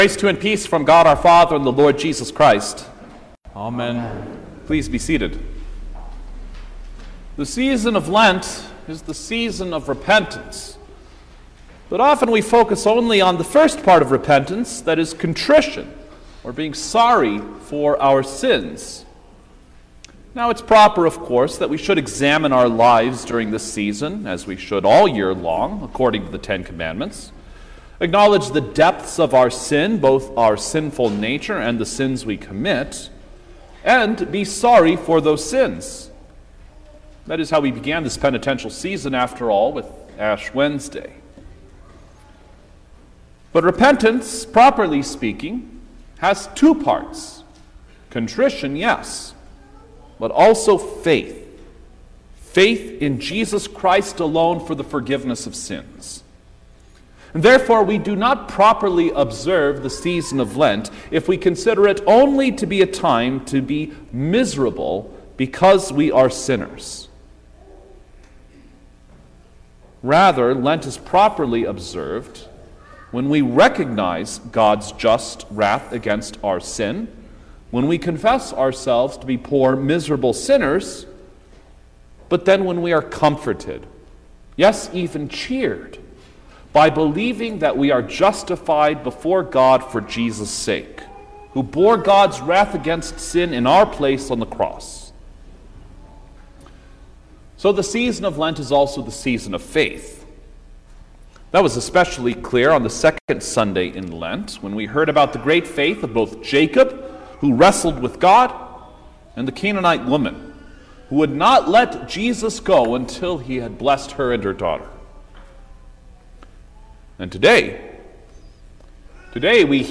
April-3_2022_The-Fifth-Sunday-in-Lent_Sermon-Stereo.mp3